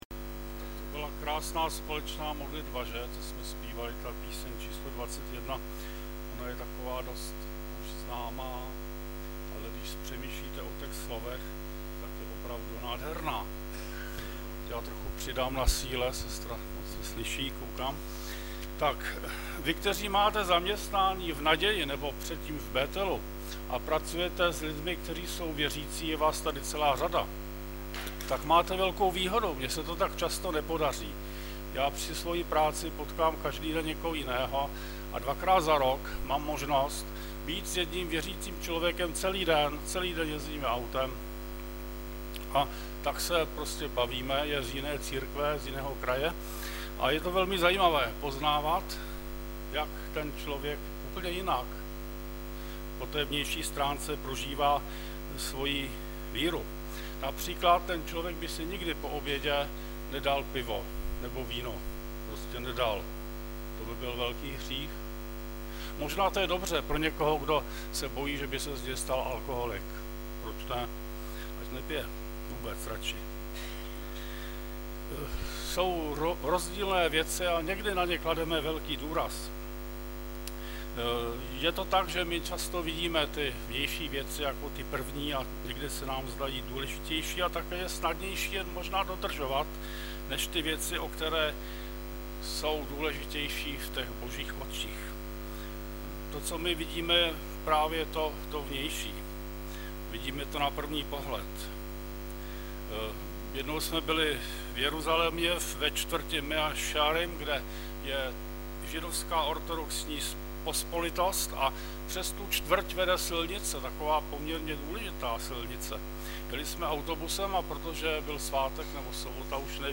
Webové stránky Sboru Bratrské jednoty v Litoměřicích.
Audiozáznam kázání si můžete také uložit do PC na tomto odkazu.